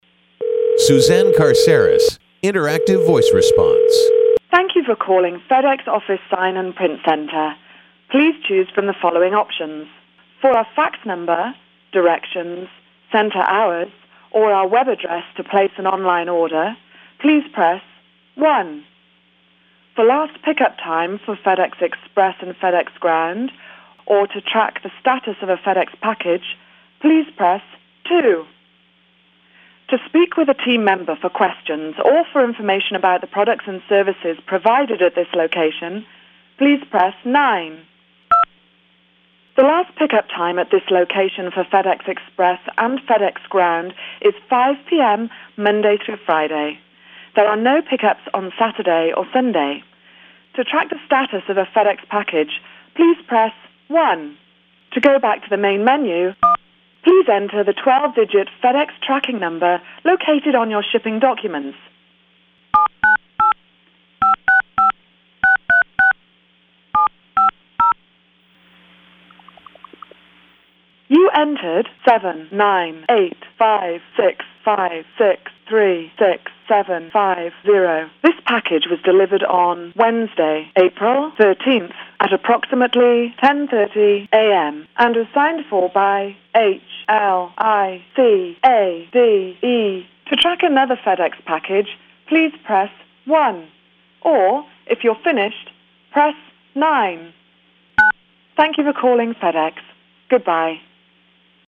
List to my Interactive Voice Response (IVR) demo here.
ivr_demo.mp3